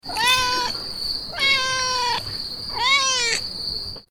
دانلود آهنگ بچه گربه در شب از افکت صوتی انسان و موجودات زنده
جلوه های صوتی
دانلود صدای بچه گربه در شب از ساعد نیوز با لینک مستقیم و کیفیت بالا